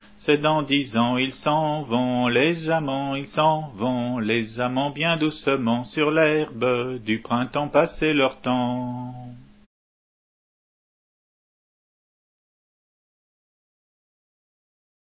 Entendu au fest deiz de la